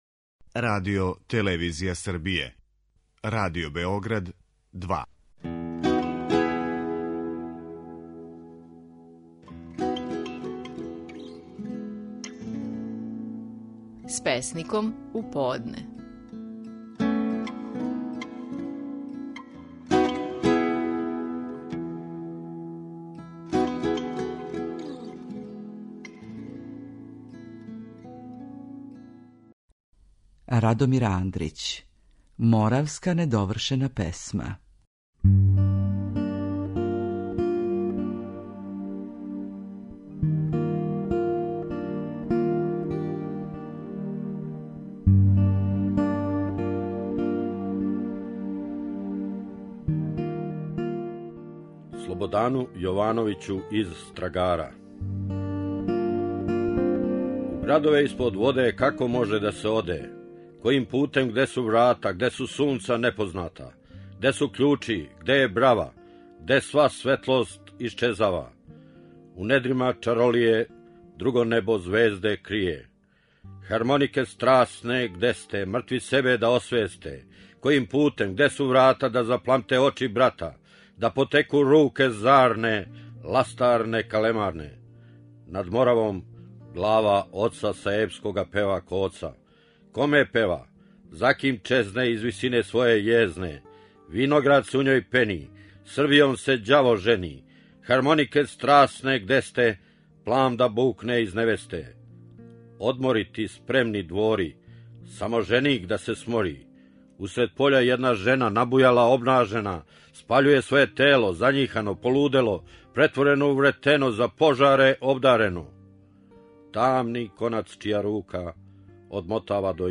Наши најпознатији песници говоре своје стихове
Радомир Андрић говори стихове своје песме „Моравска недовршена песма".